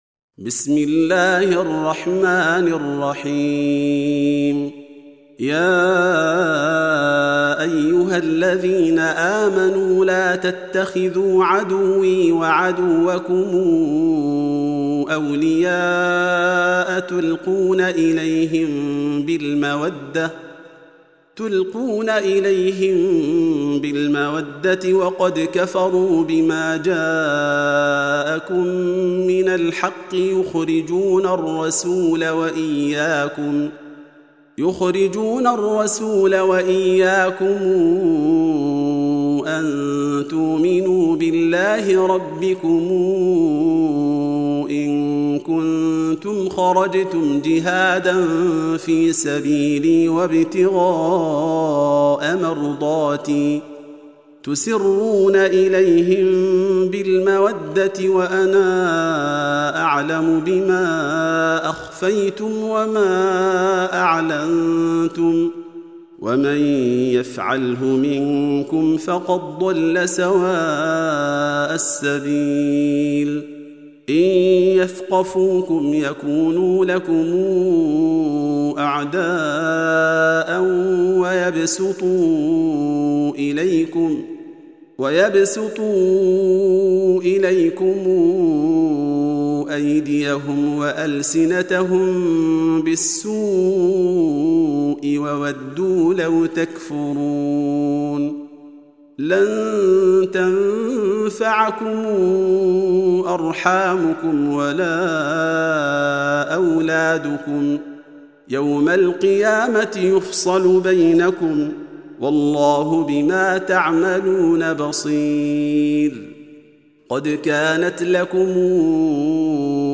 Reciting Murattalah Audio for 60. Surah Al-Mumtahinah سورة الممتحنة N.B *Surah Includes Al-Basmalah